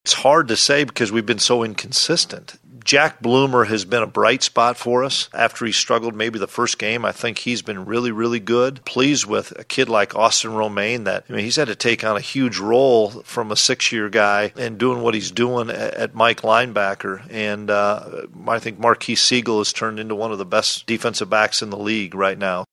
At his Tuesday press conference, Klieman was asked about what has pleased him thus far this season…